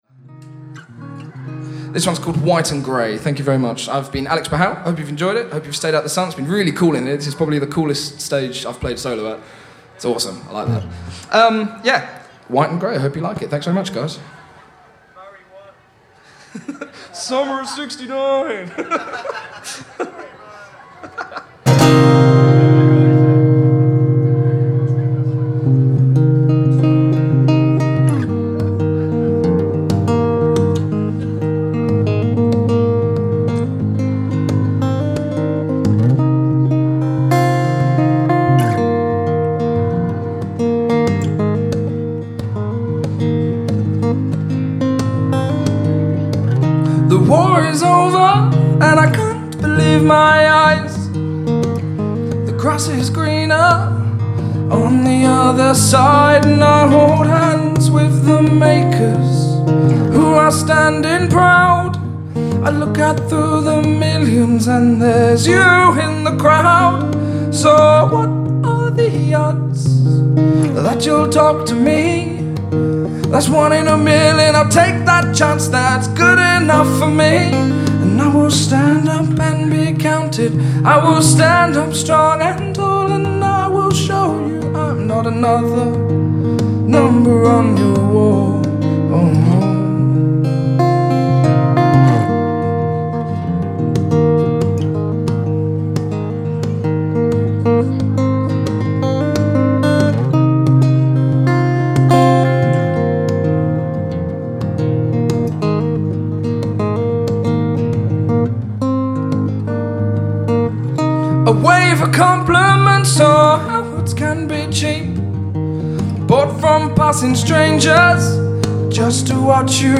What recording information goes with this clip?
Recorded Live at Tentertainment 2012